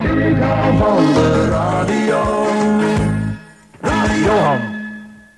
Hier enkele jingles.